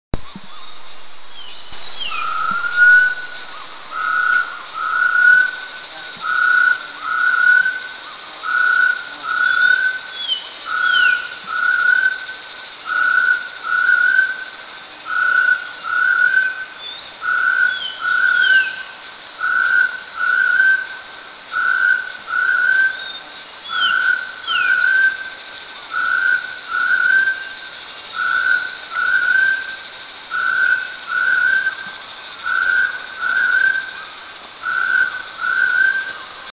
Arborophila mandellii
Chestnut-breasted Partridge
Chestnut-breastedPartridge.mp3